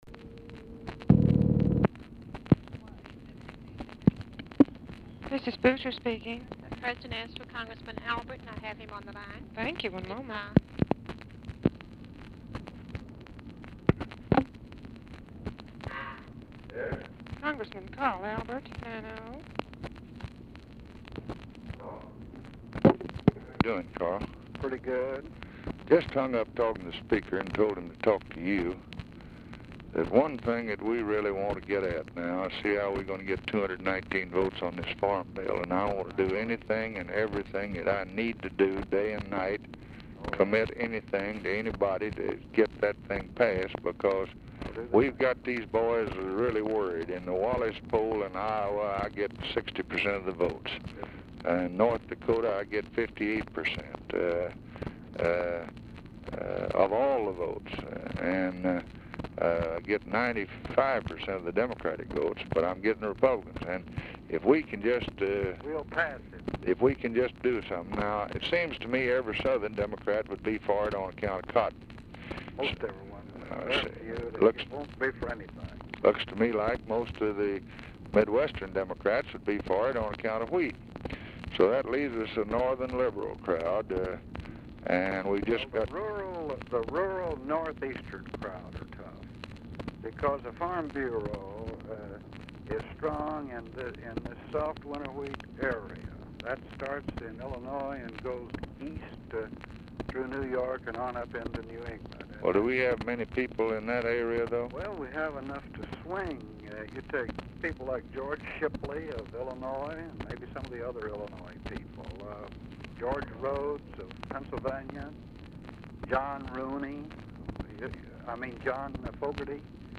Telephone conversation # 2388, sound recording, LBJ and CARL ALBERT, 3/7/1964, 12:55PM | Discover LBJ
Format Dictation belt
Location Of Speaker 1 Oval Office or unknown location
Specific Item Type Telephone conversation